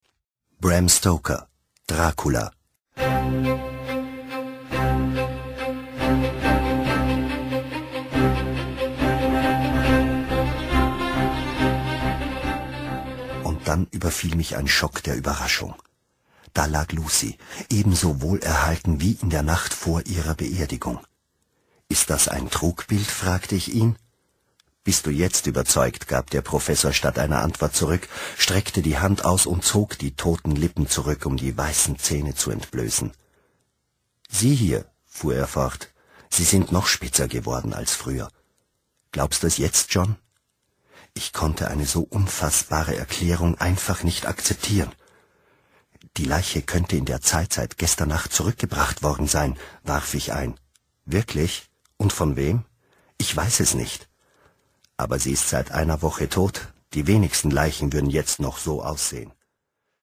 deutschsprachiger Profi-Sprecher, Regisseur, Schauspieler, Dozent, Coach / Konsulent f. Sprachgestaltung u. Dialogregie
wienerisch
Sprechprobe: Sonstiges (Muttersprache):